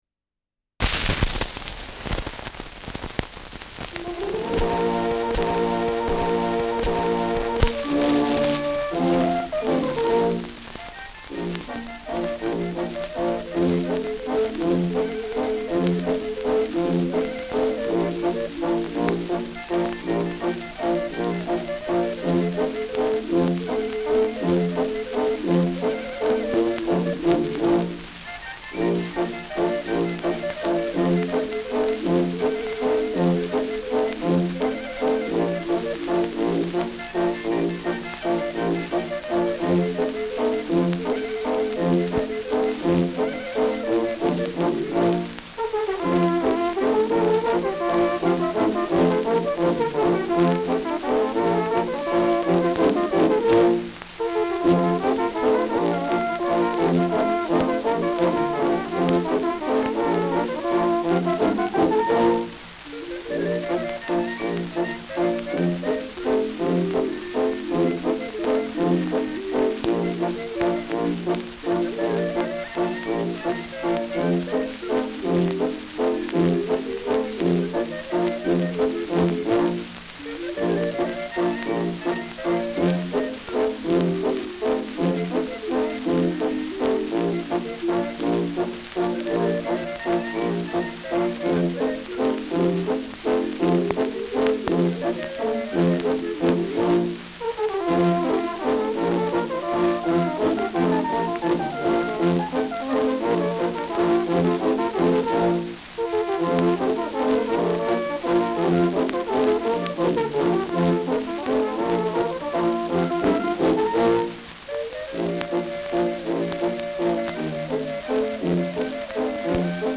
Schellackplatte
„Hängen“ in der ersten Sekunde : ausgeprägtes Rauschen